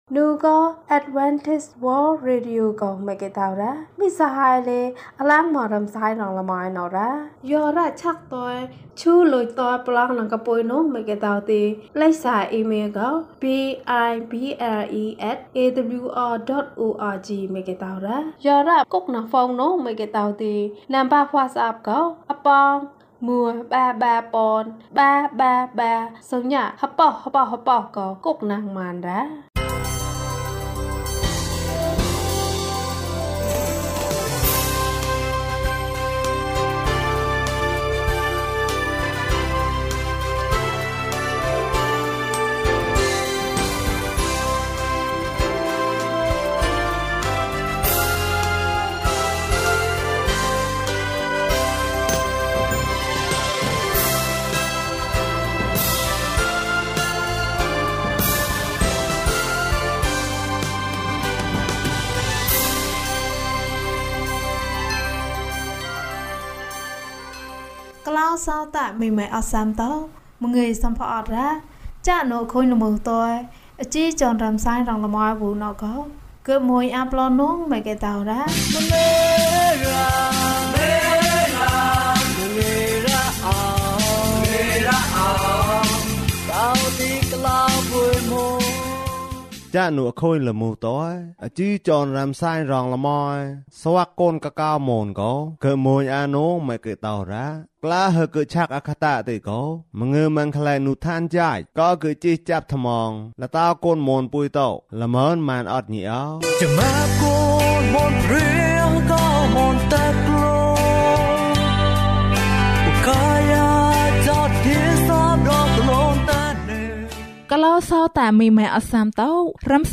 အကောင်းဆုံးသူငယ်ချင်း။၀၂ ကျန်းမာခြင်းအကြောင်းအရာ။ ဓမ္မသီချင်း။ တရားဒေသနာ။